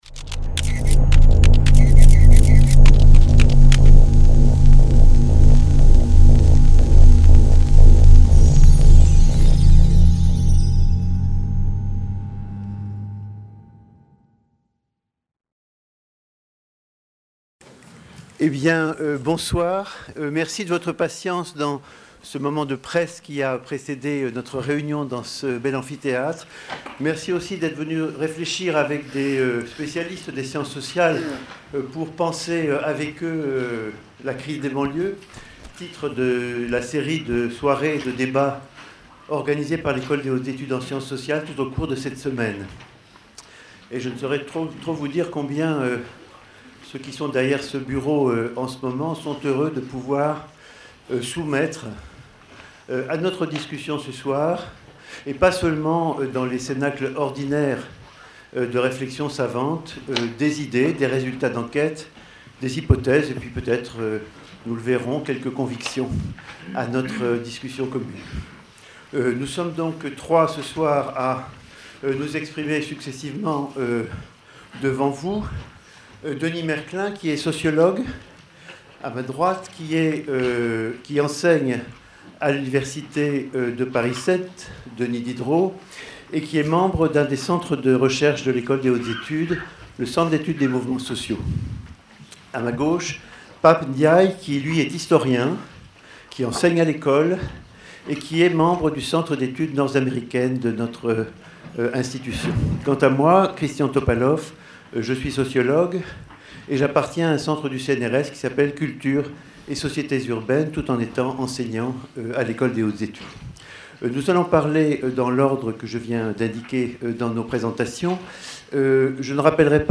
Les Éditions de l'EHESS Débats enregistrés du lundi 23 janvier 2006 au samedi 28 janvier 2006. Après le premier moment de commentaire à chaud de l'événement, l'EHESS a souhaité contribuer à établir une véritable circulation d'idées entre les acteurs sociaux et les chercheurs en sciences sociales, sociologues, économistes, anthropologues, historiens. 1 - Une société inégalitaire.